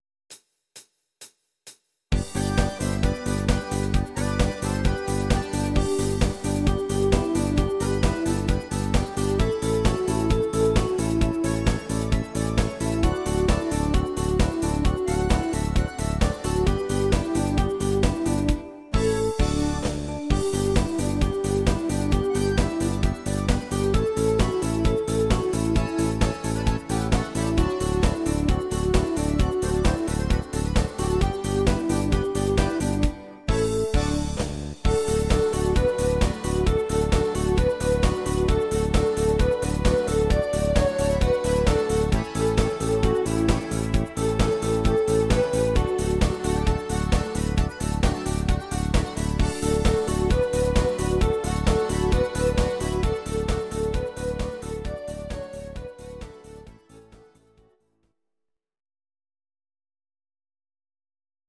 Audio Recordings based on Midi-files
Pop, German, Medleys